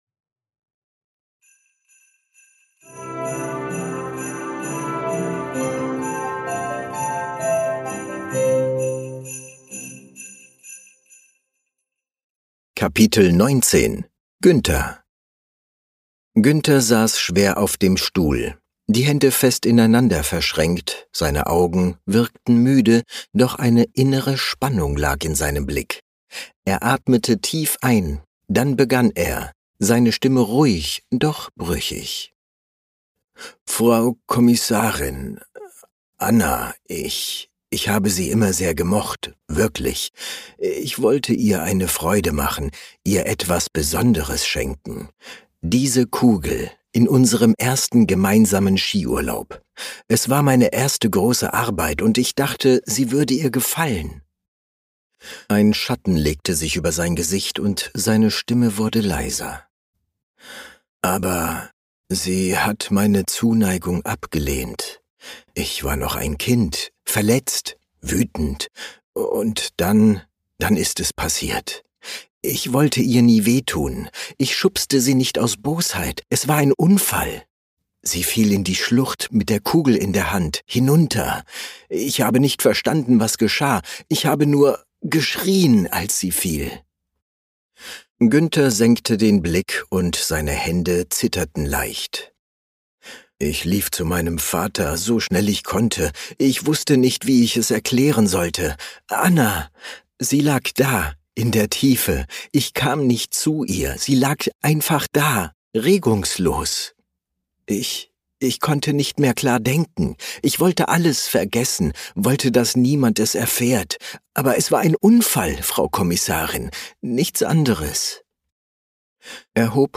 Lass dich von acht verzaubernden Stimmen in die